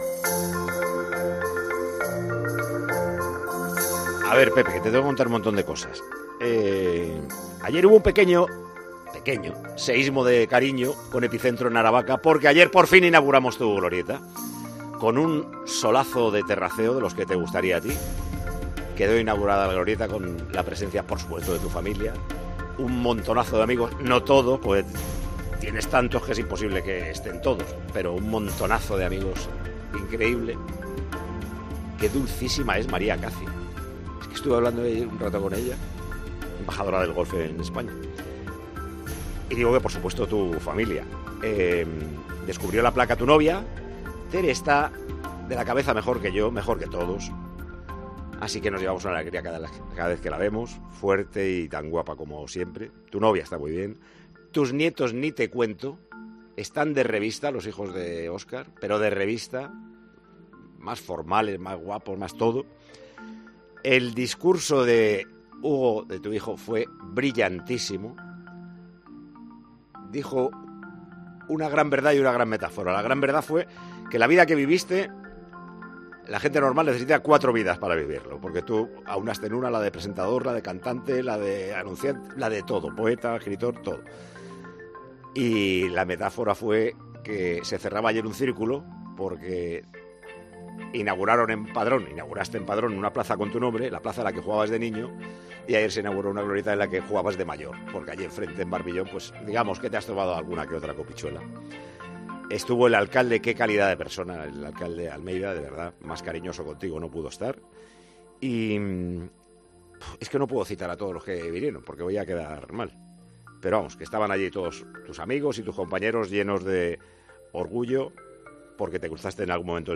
El director y presentador de Tiempo de Juego le dedica unas palabras a 'La Leyenda' antes del comienzo del programa de este sábado.
Escucha íntegras estas emotivas palabras en el audio que aparece a continuación y que formó parte de la primera hora del programa de este sábado 17 de febrero.